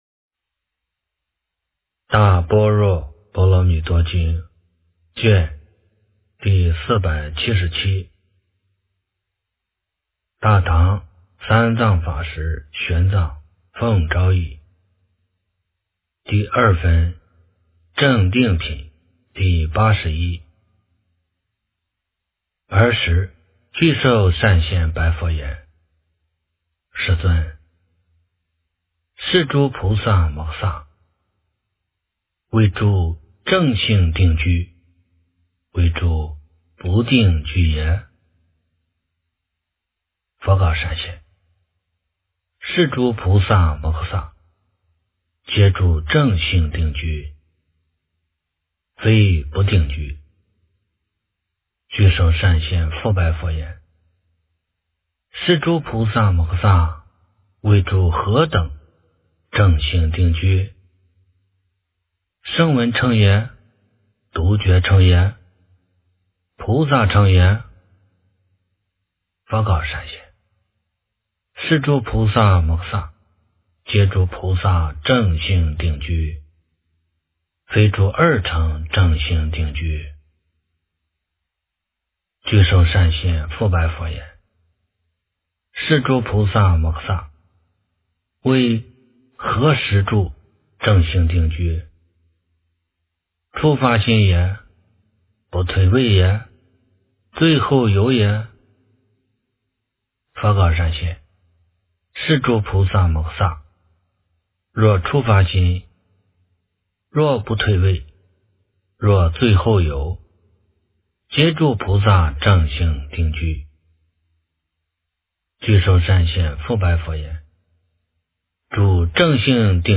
大般若波罗蜜多经第477卷 - 诵经 - 云佛论坛